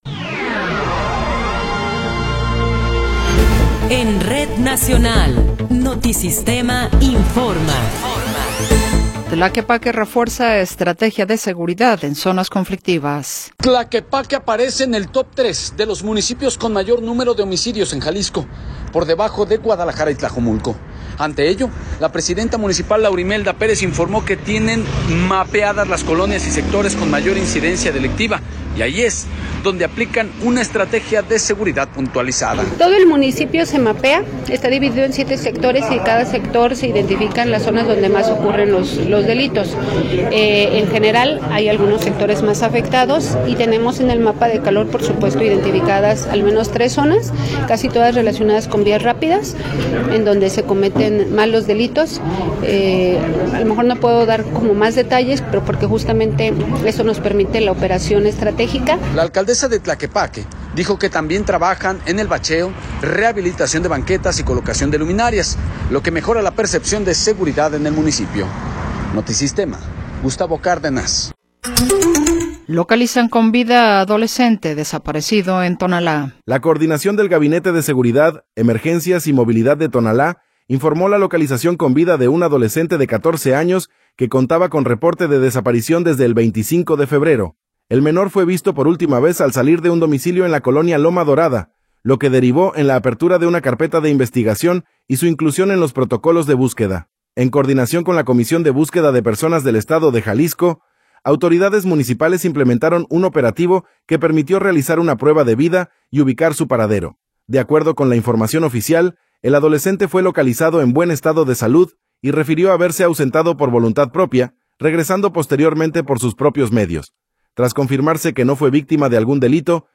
Noticiero 16 hrs. – 26 de Febrero de 2026